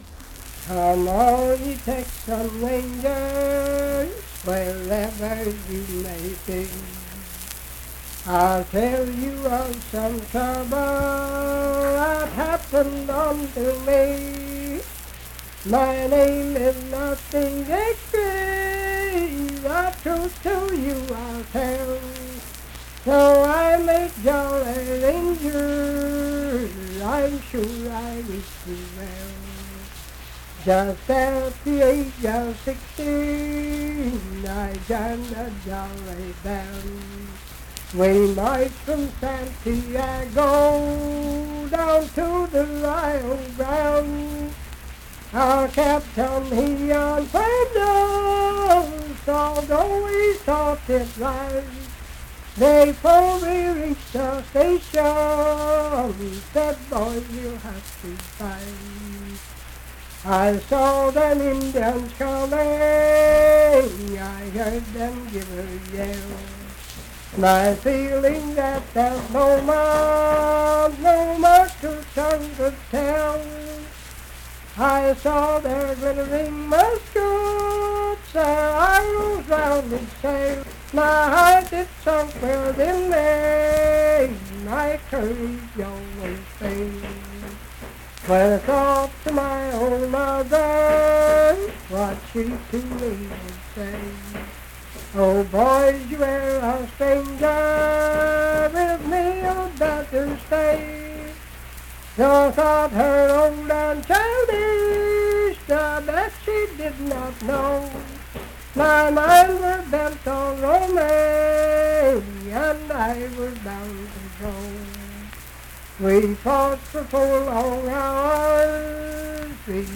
Unaccompanied vocal music
Performed in Ivydale, Clay County, WV.
Voice (sung)